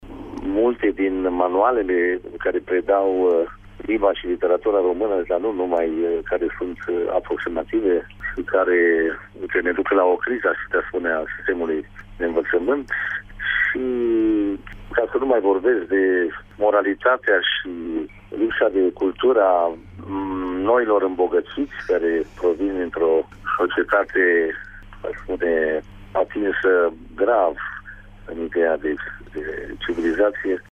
Asta a spus directorul UNITER, Ion Caramitru, invitat, prin telefon, la emisiunea Sens Unic, de la Radio Tîrgu-Mureș.